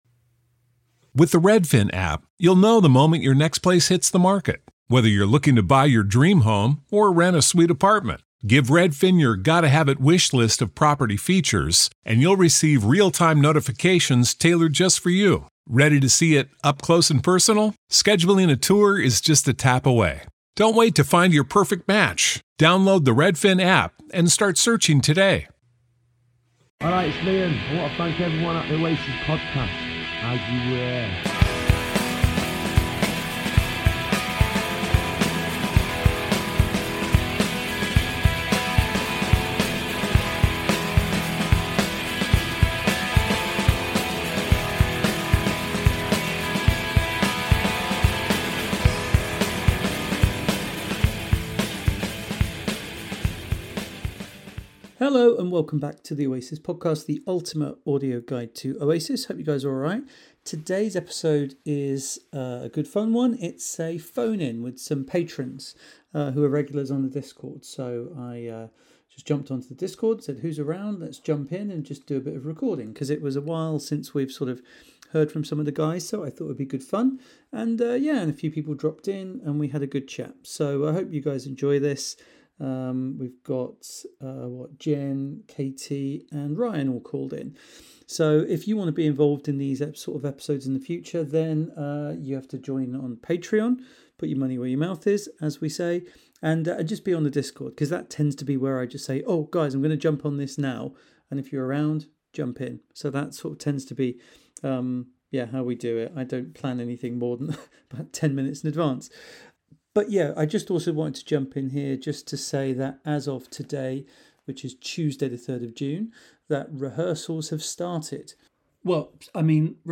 Today's episode is a Patreon call-in